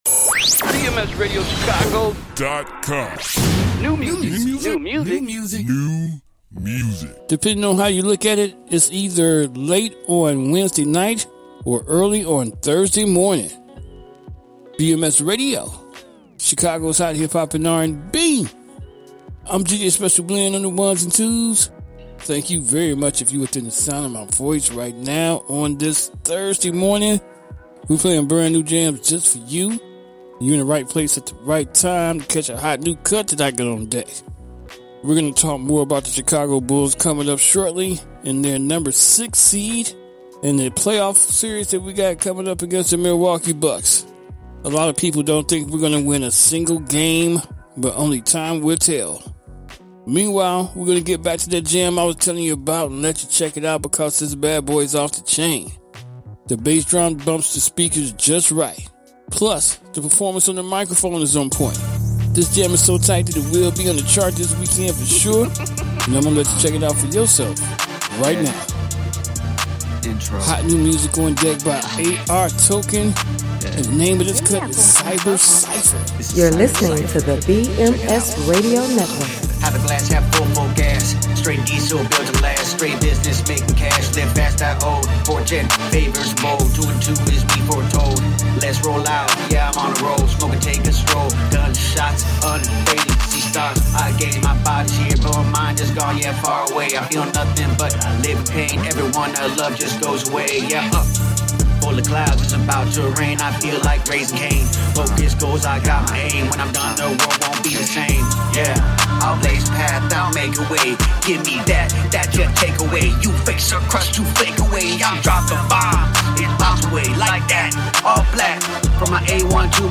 Chicago’s Hot Hip-Hop and R&B.